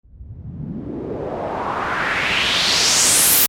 Dj Transition Sound Effect Free Download
Dj Transition